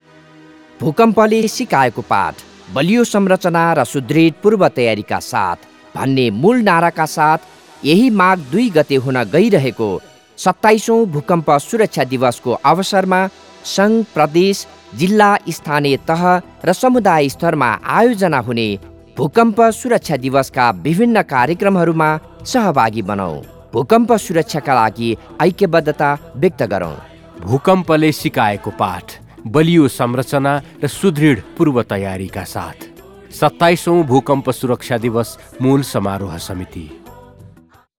ESD Audio/Video PSA
Earthquake PSA 2.wav